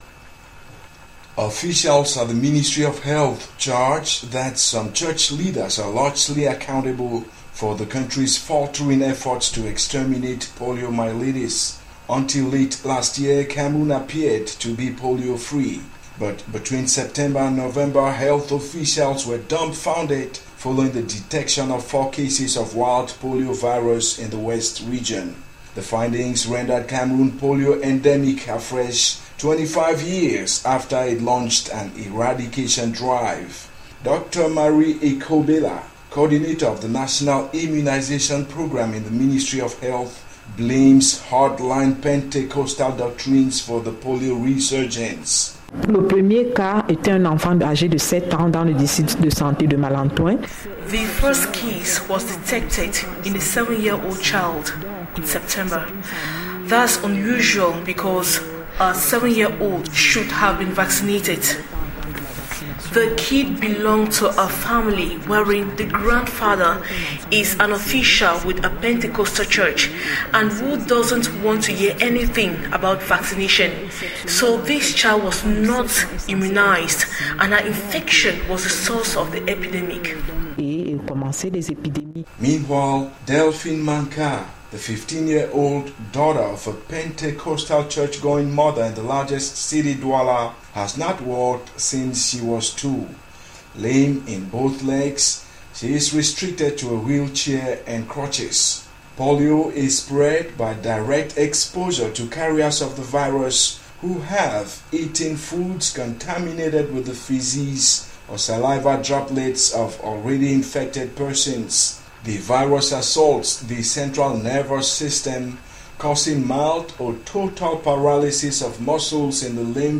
interviews about poliomyelitis